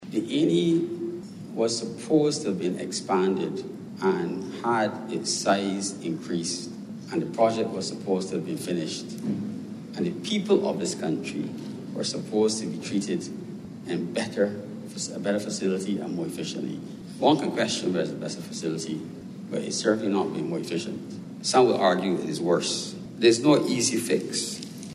Independent Senator, Dr. Christopher Maynard, who’s a surgeon spoke as debate on government’s budget continued in the House of Assembly.